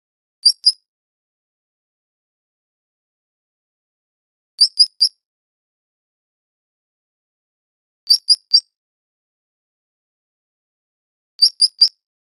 Dart Frog Calls